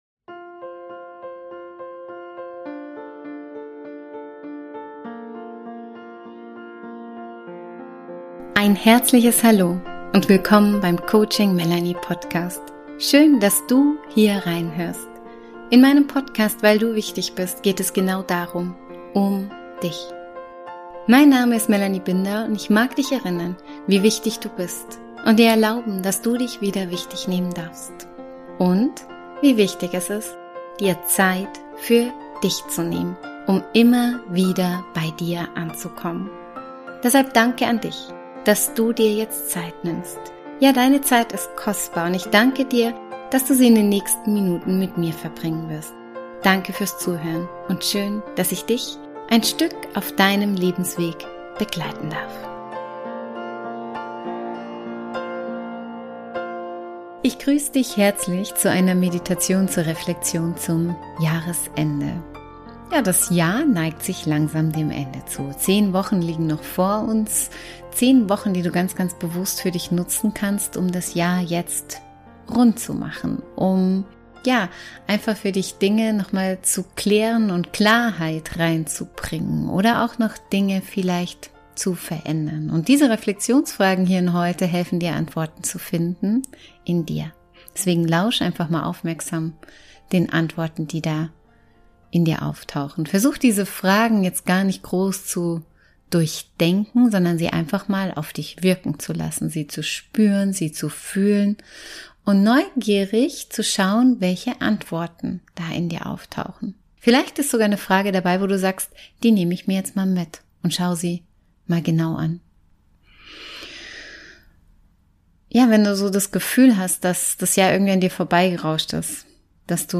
In dieser Folge lade ich dich zu einer besonderen Jahresend-Reflexion ein. Gemeinsam tauchen wir in eine geführte Meditation ein, die dich dabei unterstützt, das vergangene Jahr achtsam zu betrachten und neue Klarheit für dich zu gewinnen.